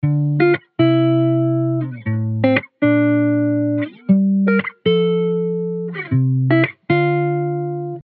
гитара
мелодичные
спокойные
без слов
Просто хорошая мелодия